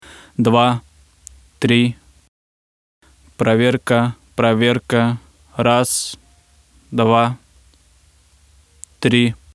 Шипение при записи
При записи голоса слышен шум схожий с шумом шипения телевизора, можете послушать вложенное аудио, помогите, пожалуйста, уже не знаю что делать Когда громко...